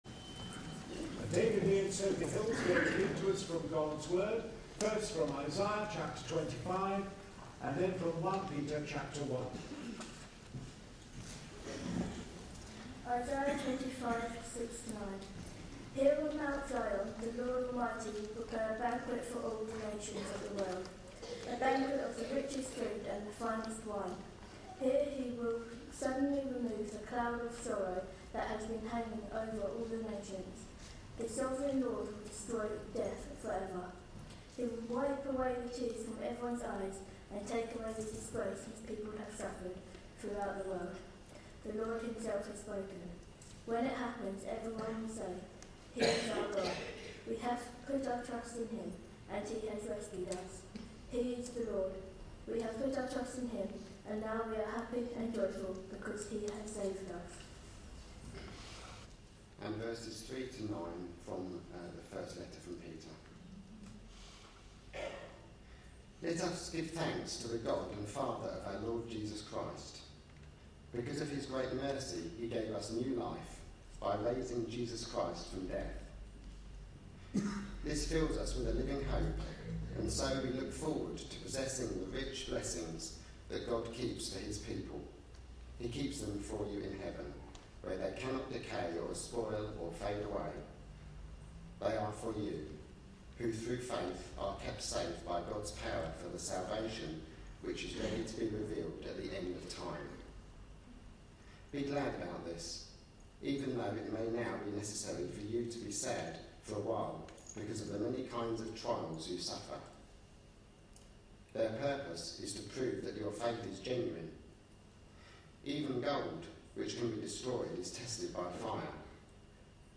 A sermon preached on 4th November, 2012, as part of our The Message of Peter for Today series.
The sermon was part of a service commemorating those died in the past year (customarily held around All Saints Day).